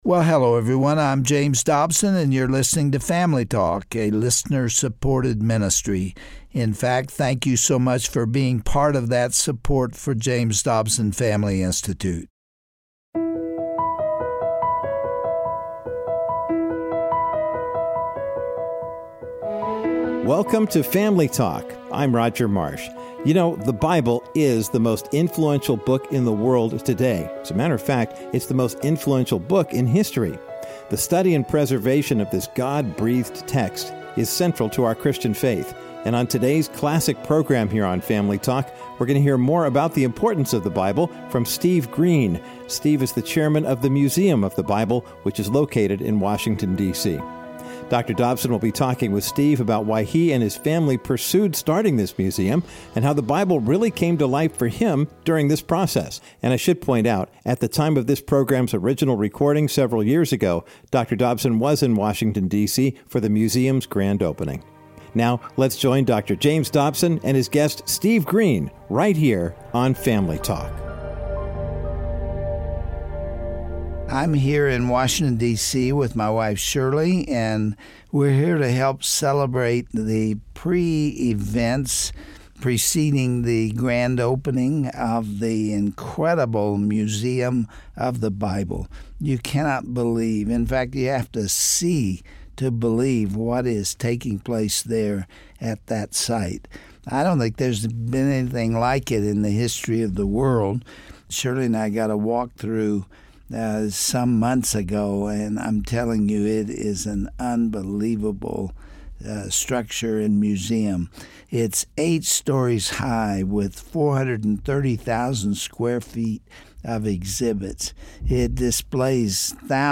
With the opening of the Museum of the Bible in Washington, D.C. in 2017, Christians have the opportunity to learn more about the narrative, history and impact of the Word. On today’s edition of Family Talk, Dr. James Dobson interviews Steve Green, president of Hobby Lobby and chairman of the Museum of the Bible, to discuss the incredible journey that took place from the museum’s inception to its grand opening.